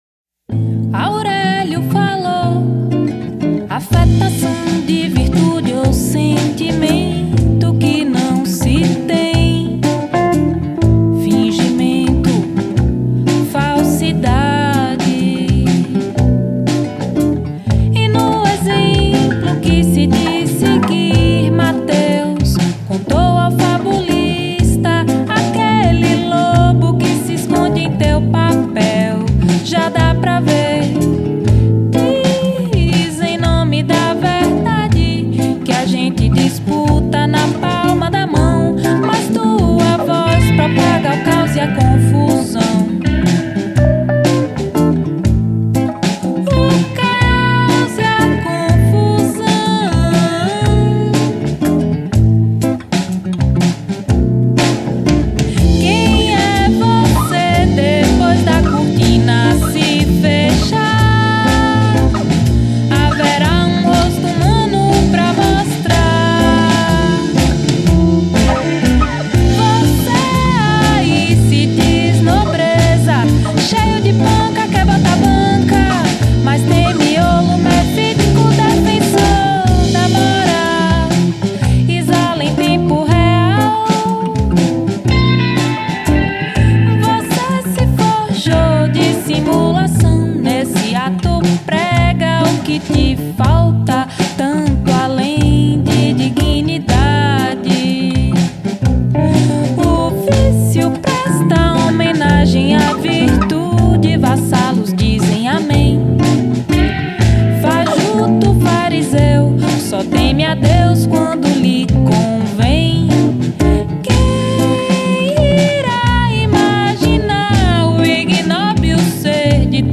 traz uma música de letra forte em contraponto à sua voz leve
voz e violão nylon
baixo
synths e piano elétrico
guitarra
bateria